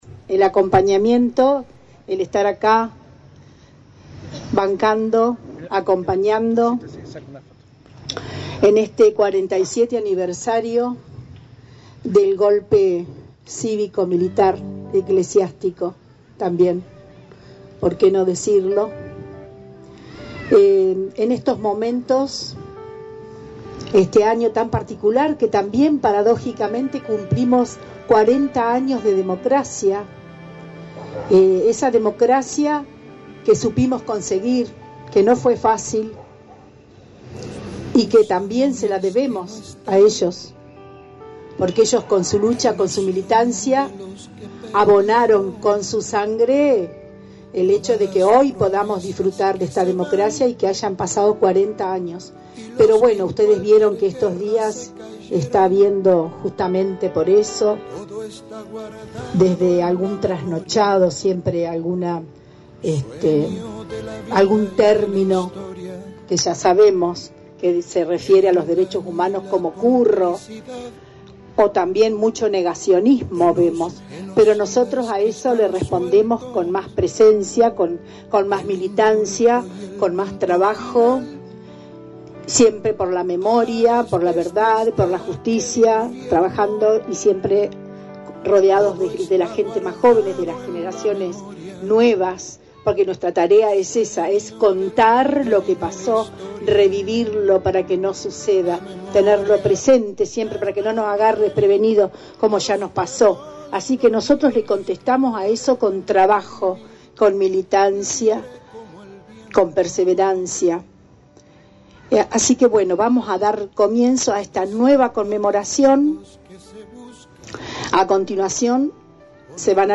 Como cada 24 de marzo tuvo lugar en la tarde-noche del viernes en el monolito que recuerda a los desaparecidos florenses, ubicado en Plaza Mitre, el acto central por el Día de la Memoria.
Acto-Dia-Memoria.mp3